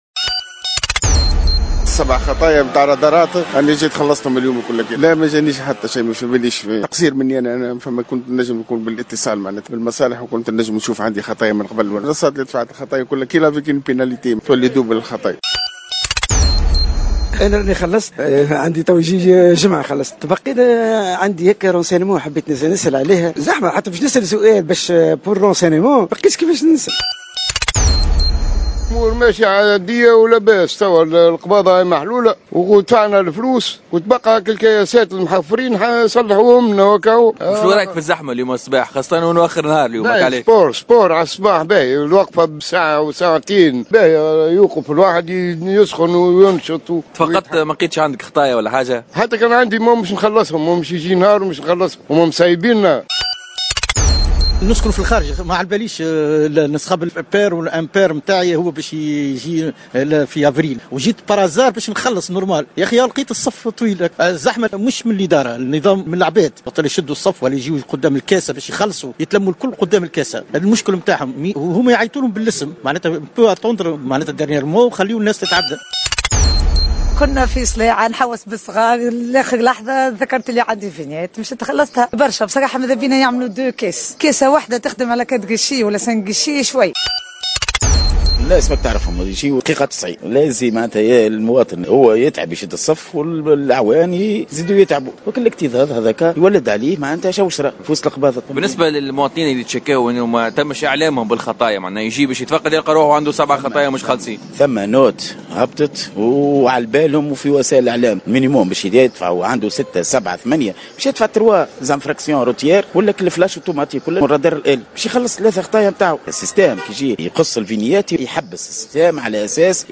آراء المواطنين في التسجيل التالي